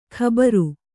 ♪ khabaru